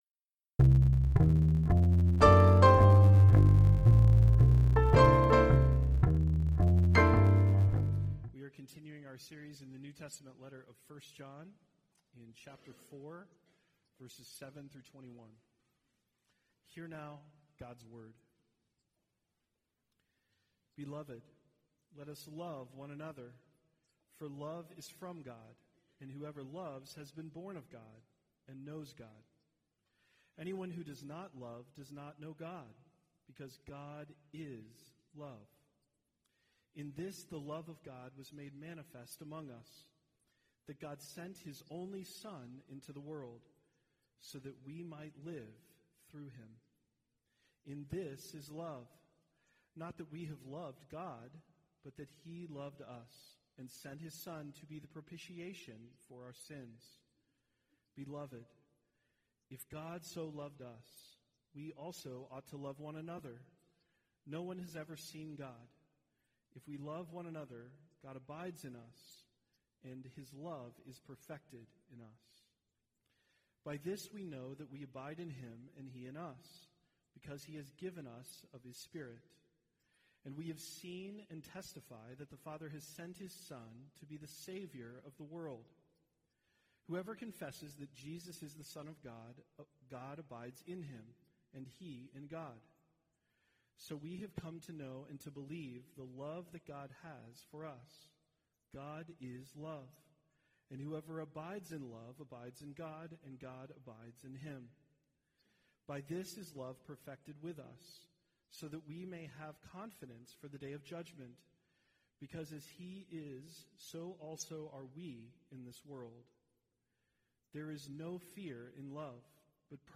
Passage: 1 John 4:7-21 Service Type: Sunday Worship « What Is Love?